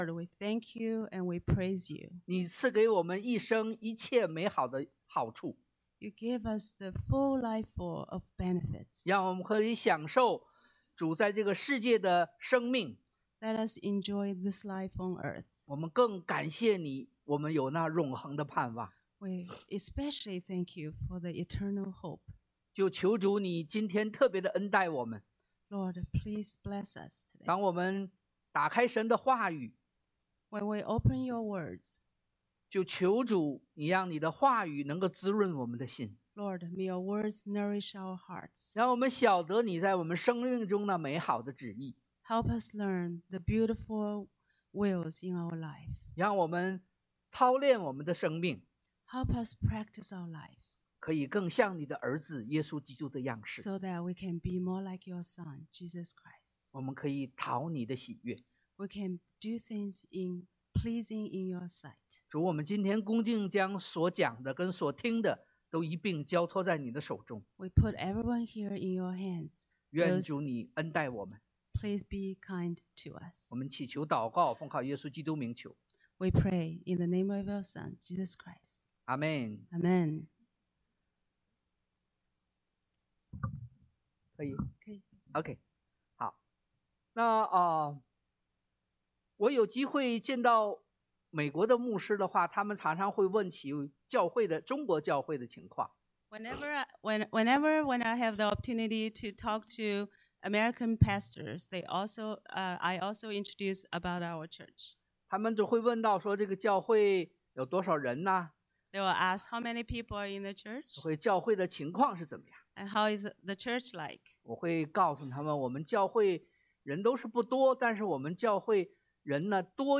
約壹 1 John 3:11-24 Service Type: Sunday AM 1.